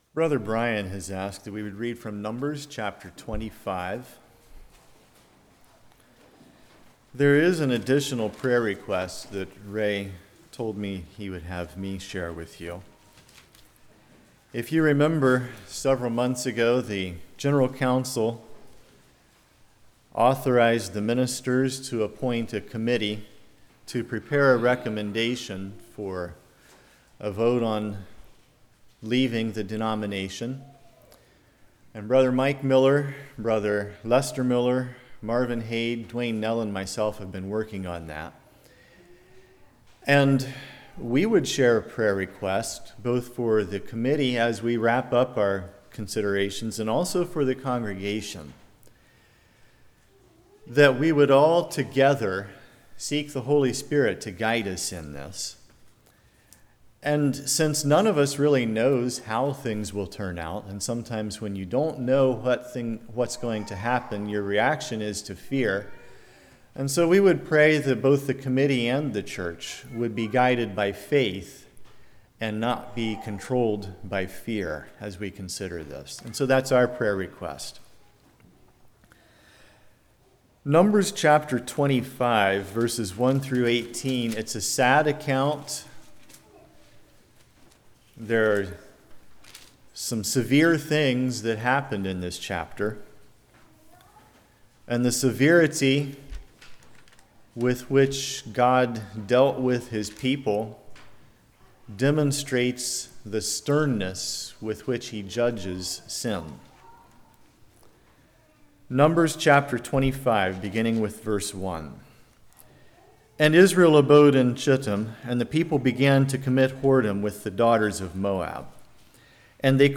Numbers 25:1-18 Service Type: Morning Israeli Camp The Bait of Lust Every Sin Has A Beginning A Time To Act and Time to be Still Phinehas Confronts Sin Are My Motives Pure?